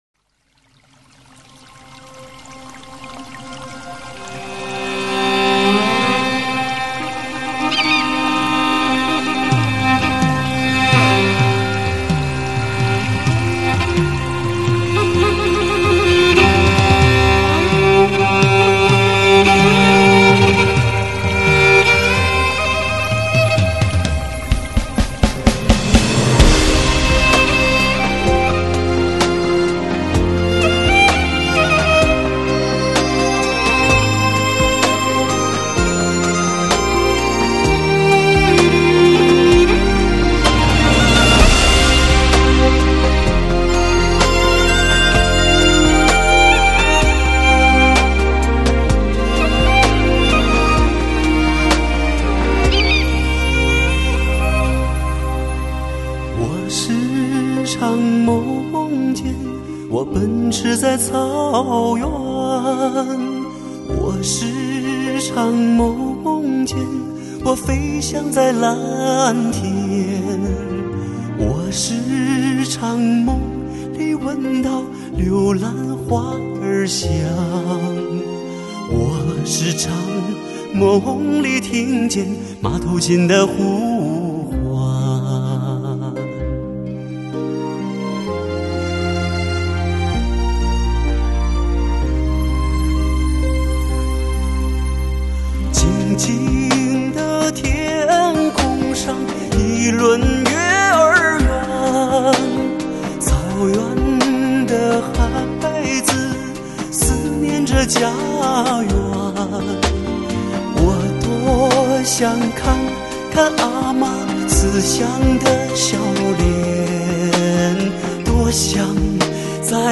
马头琴